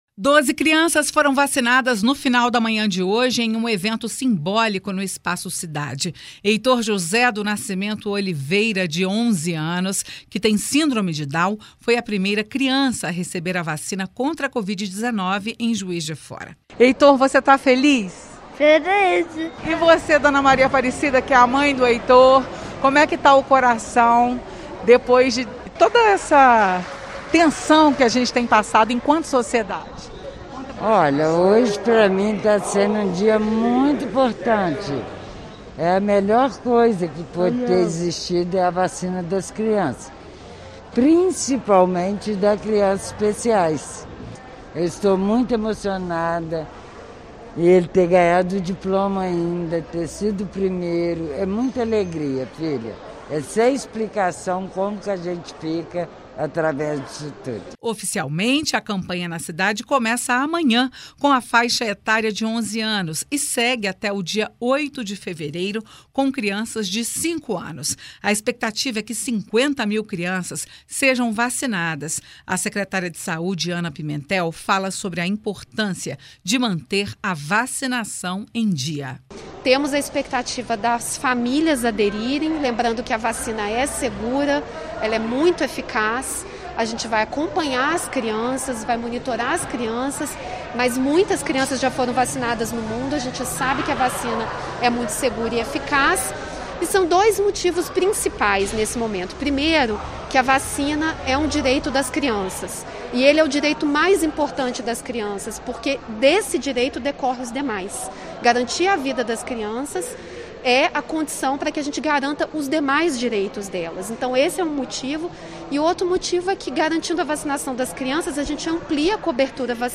A reportagem da FM Itatiaia acompanhou o evento.
19.01_Materia-evento-marca-inicio-de-vacinacao-infantil-em-JF.mp3